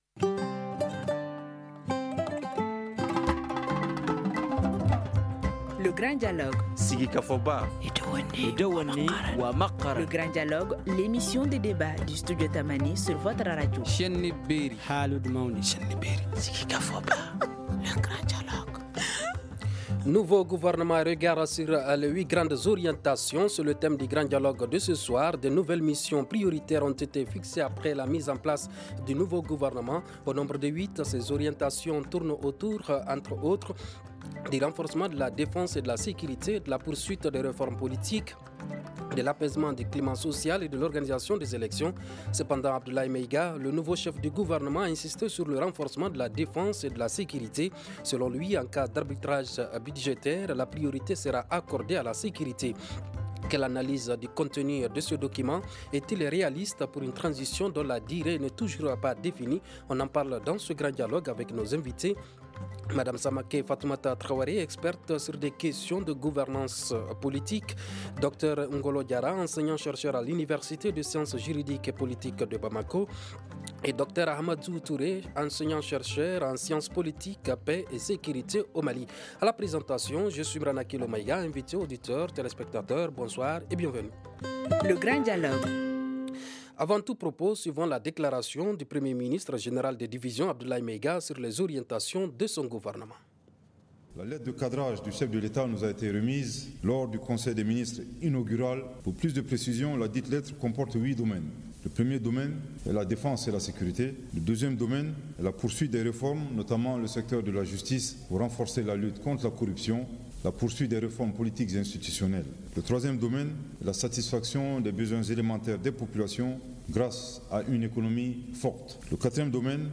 On en parle dans ce Grand Dialogue avec nos invités :